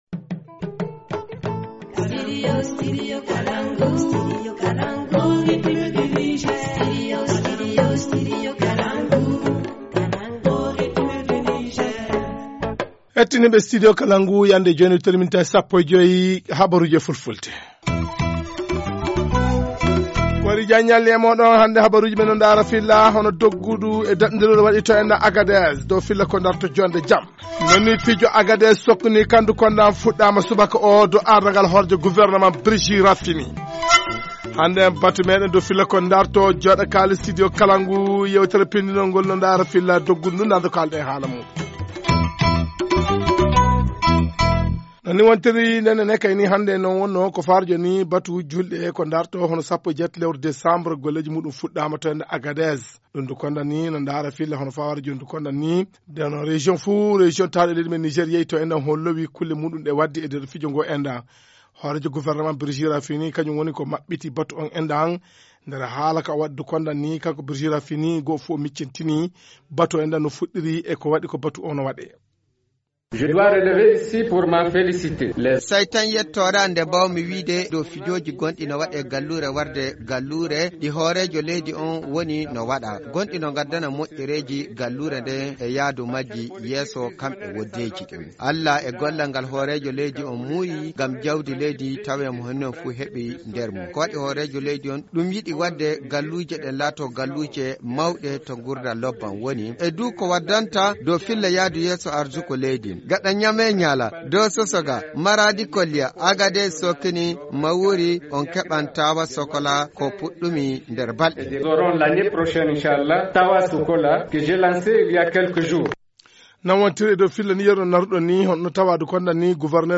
2.Le premier ministre a procédé ce même vendredi au lancement officiel des festivités de la fête du 18 décembre dénommée « Agadez Sokni », à Agadez. Voici la déclaration de Brigi Rafini et les propos du gouverneur de la région d’Agadez.